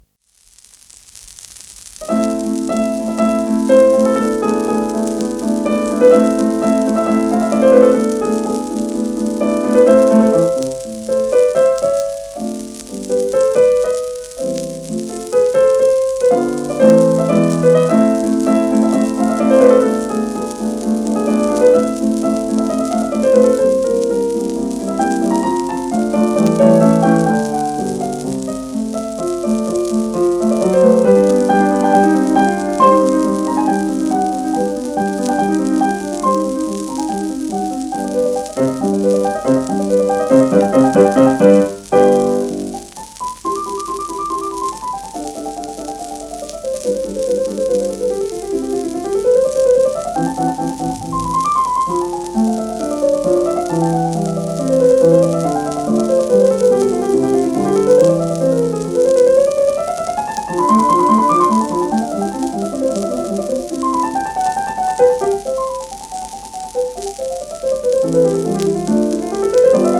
ディヌ・リパッティ(P:1917-50)
盤質A- *軽微な小キズ,軽い反り
シェルマン アートワークスのSPレコード